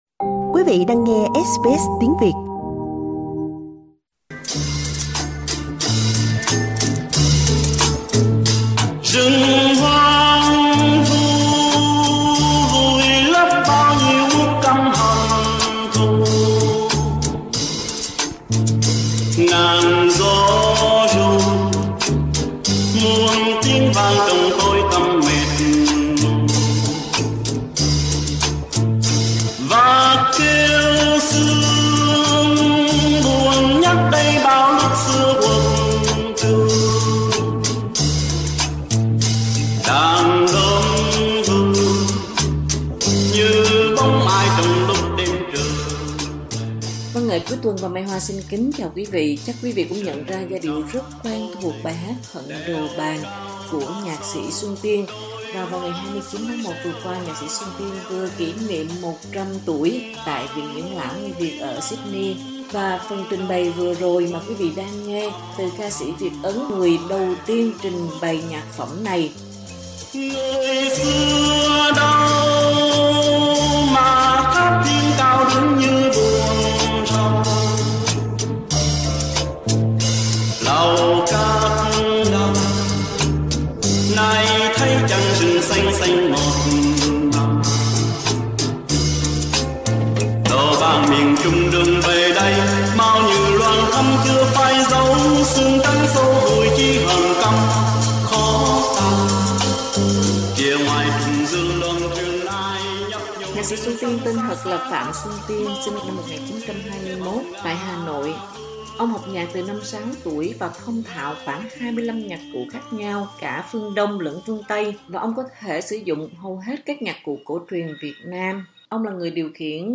SBS có cuộc trò chuyện với ông vào dịp đặc biệt này. Ở tuổi 100 ông vẫn còn rất minh mẫn, nhớ rõ những chuyện xưa và có thể tiếp chuyện gần 20 mà không hề có dấu hiệu mệt mỏi. Khi được hỏi ông chúc hay gởi gắm gì vào sinh nhật thứ 100 của mình thì người nhạc sĩ của Hận Đồ Bàn, Khúc Hát Ân Tình, Duyên Tình... đã nói gì?